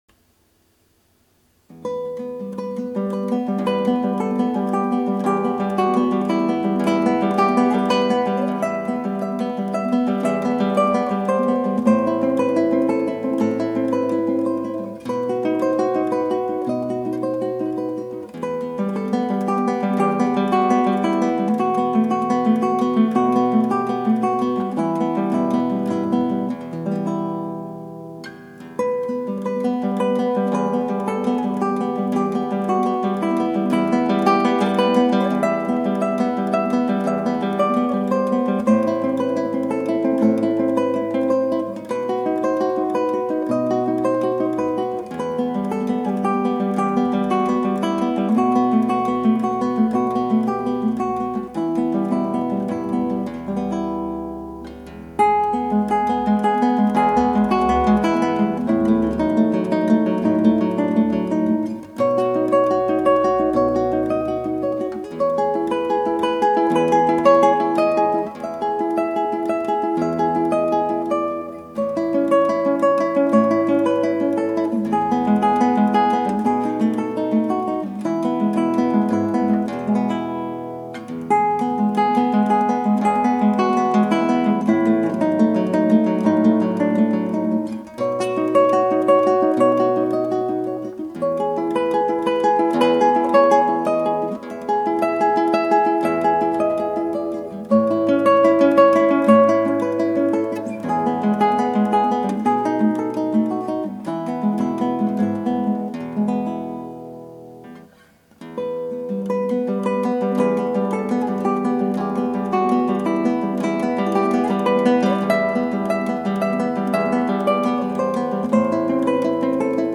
クラシックギター　ストリーミング　コンサート
「ロマンス」 　スペイン民謡